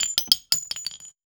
weapon_ammo_drop_21.wav